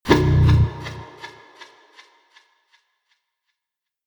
Tiếng Vang nham hiểm, kinh dị
Thể loại: Âm thanh hung dữ ghê sợ
Description: Tiếng vang nham hiểm, kinh dị gợi cảm giác rùng rợn, ám ảnh, lạnh sống lưng, u tối và đầy đe dọa. Âm thanh vang vọng sâu hun hút, méo mó, kéo dài trong không gian trống rỗng. Hiệu ứng này thường pha trộn tiếng vọng hang động, tiếng gió rít, âm bass thấp, tiếng kim loại cọ xát, tạo cảm giác căng thẳng, hồi hộp, sợ hãi.
tieng-vang-nham-hiem-kinh-di-www_tiengdong_com.mp3